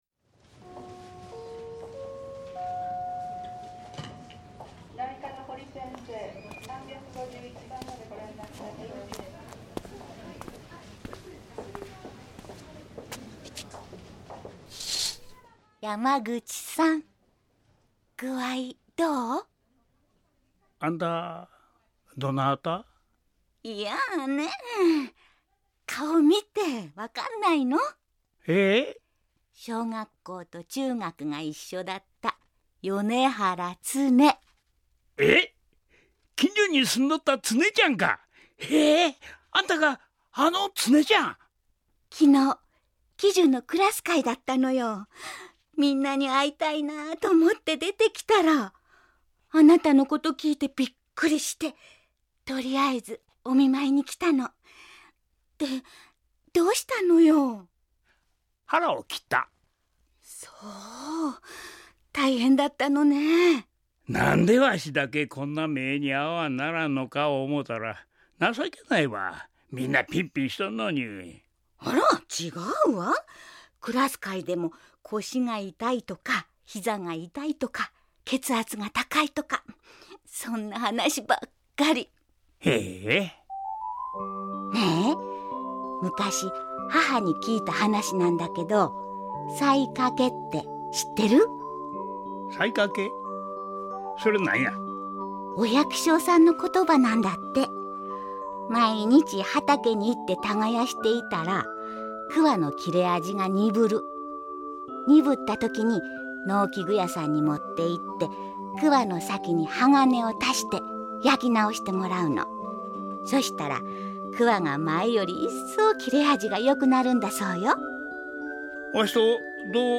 ●ラジオドラマ「LIFE」